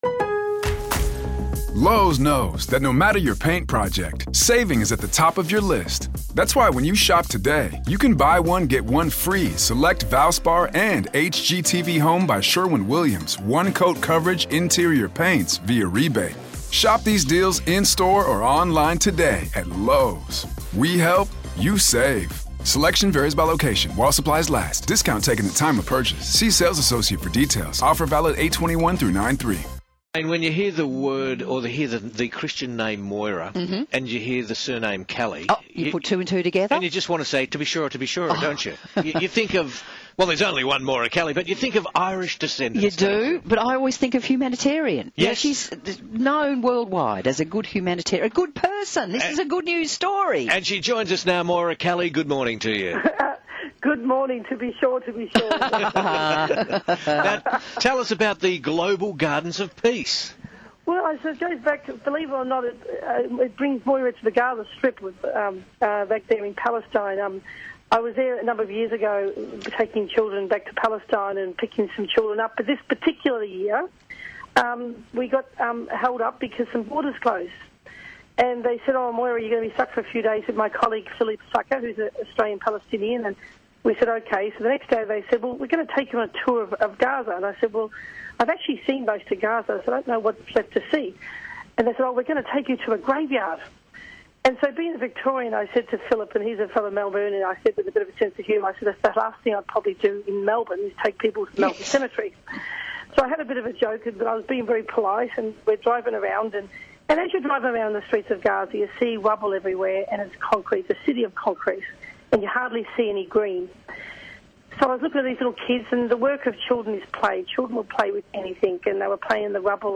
Moira Kelly Interview on the Big Backyard